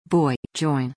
BOY/bɔɪ/, JOIN/dʒɔɪn/
boy.mp3